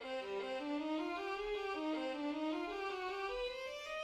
The third movement is a scherzo in C minor.
It is followed by two softer strokes and then followed by even softer strokes that provide the tempo to this movement, which includes references to Jewish folk music.